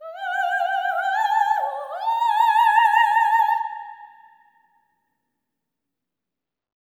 OPERATIC13-L.wav